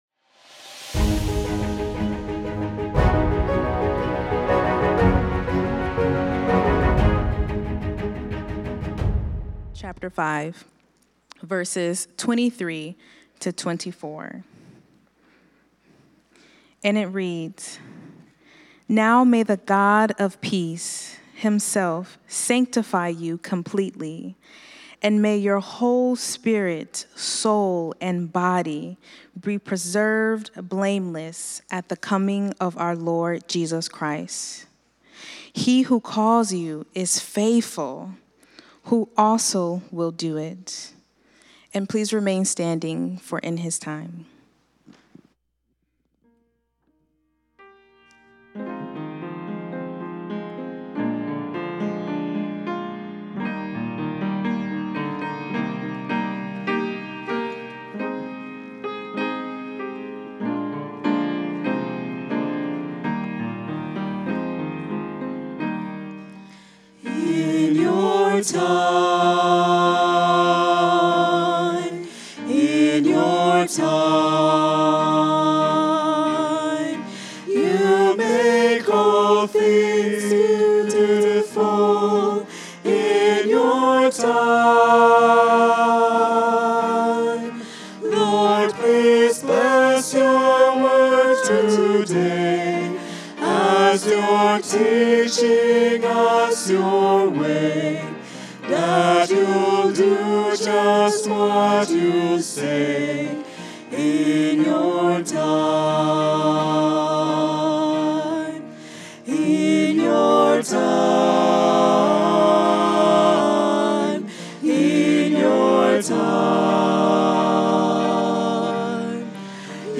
Sermon Archive – Sacramento Central Seventh-day Adventist Church